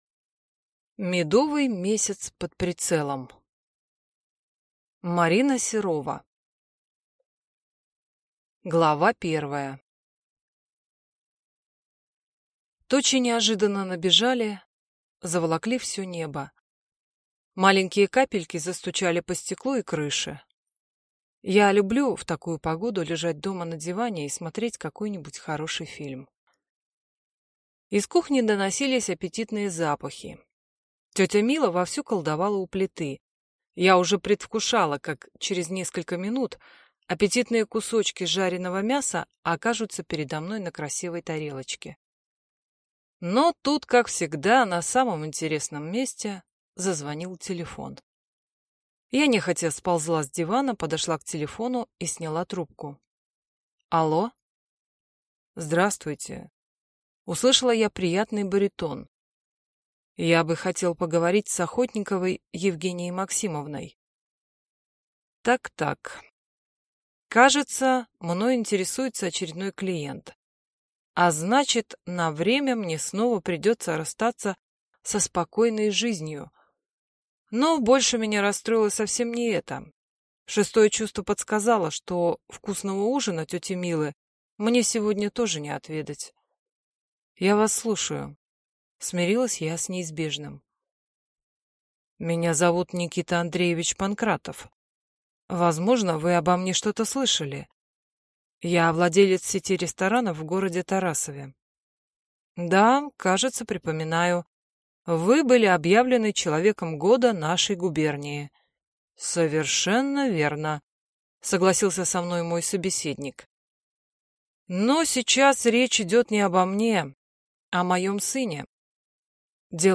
Аудиокнига Медовый месяц под прицелом | Библиотека аудиокниг